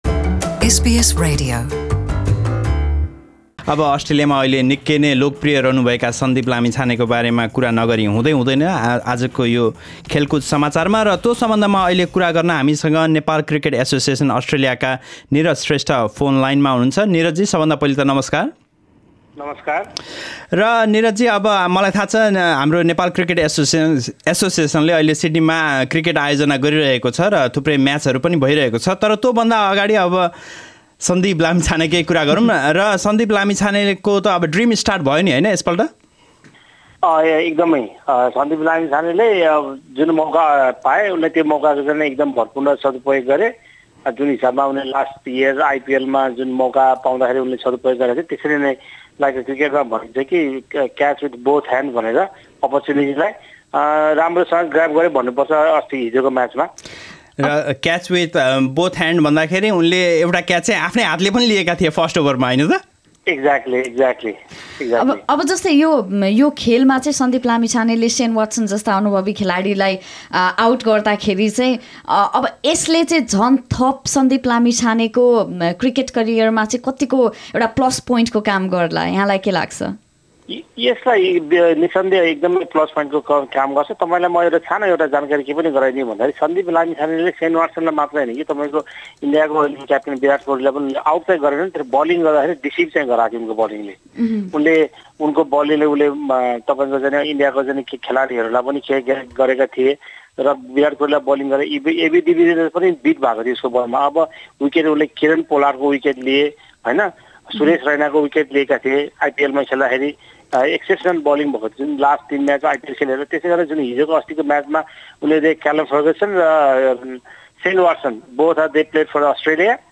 सुनौ उक्त कुराकानीको अंश।